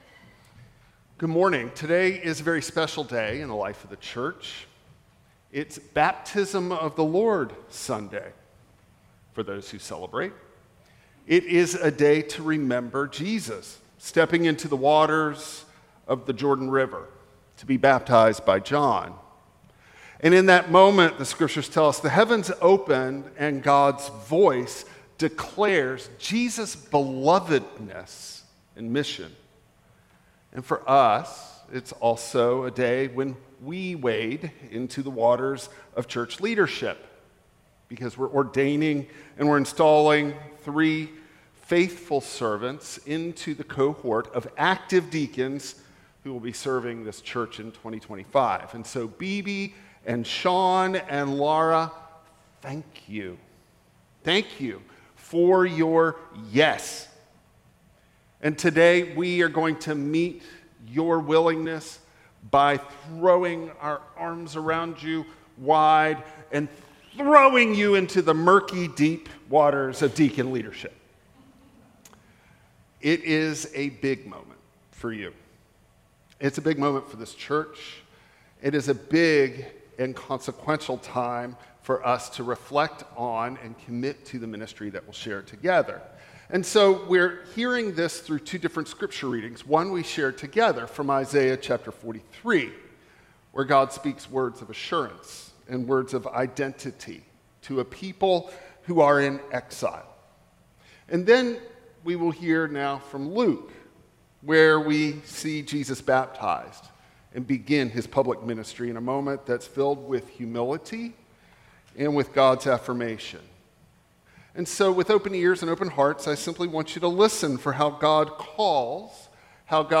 21-22 Service Type: Traditional Service Let Jesus’ baptism shape your calling.